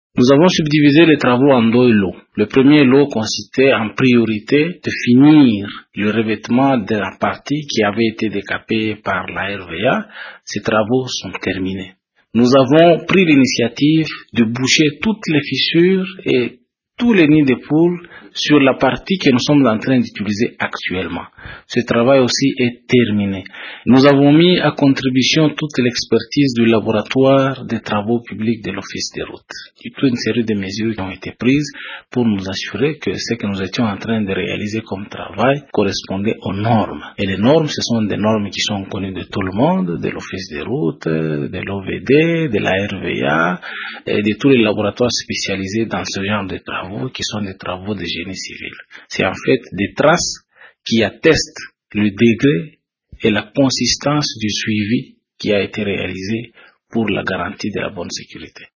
Laurent Kahozi explique comment les travaux ont jusque-là été exécutés :